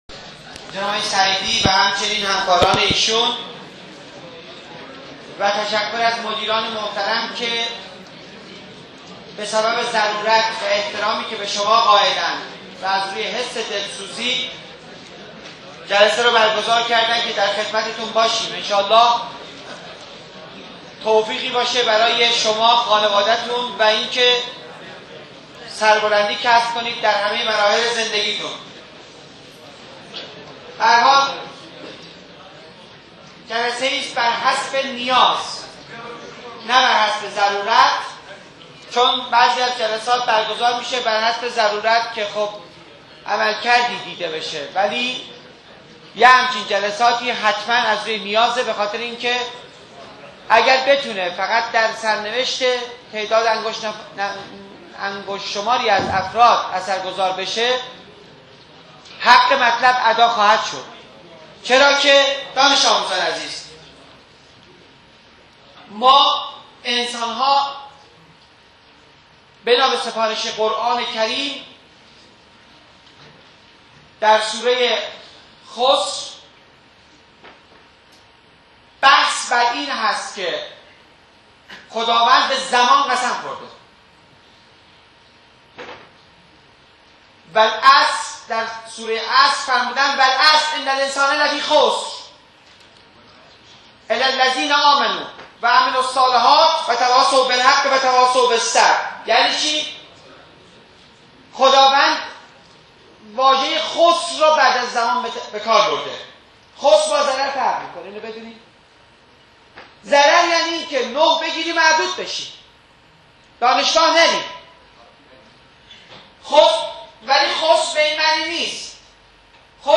فایل صوتی سمینار مشاوره